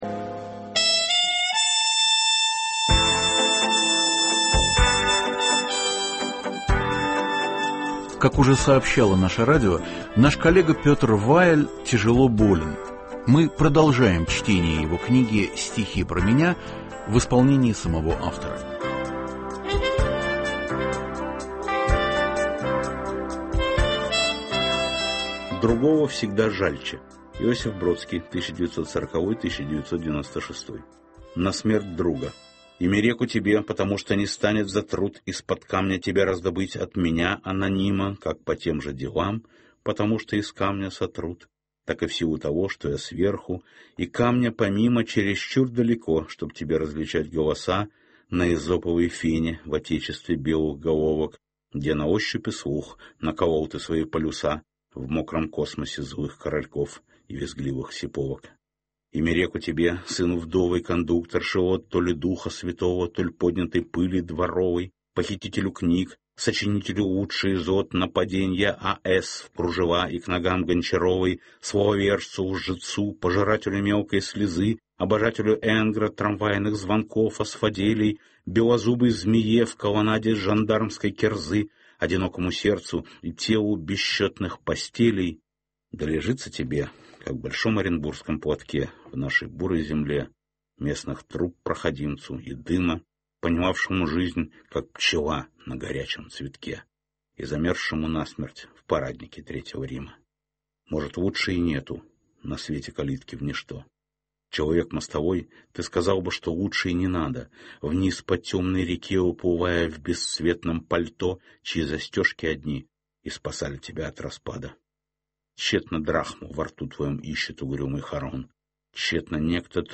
"Стихи про меня" - книга Петра Вайля в авторском чтении.